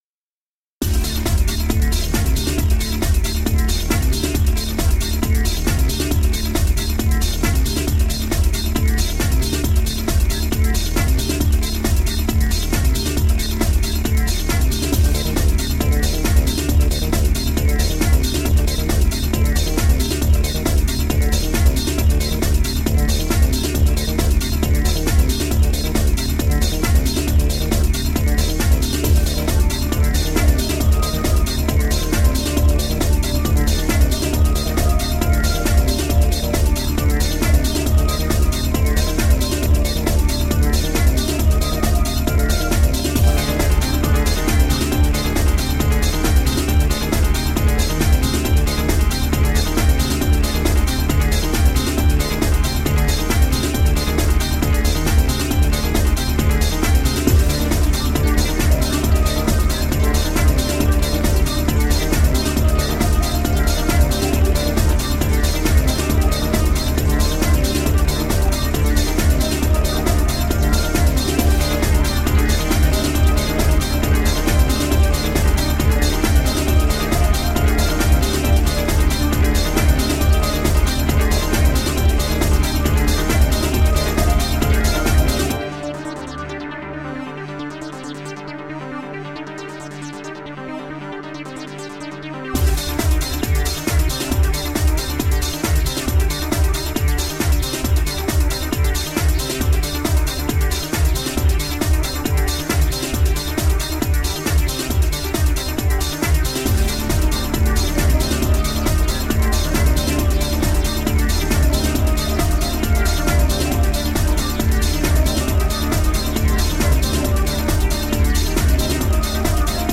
Sublime and ethereal electronic music.
Tagged as: Electronica, Techno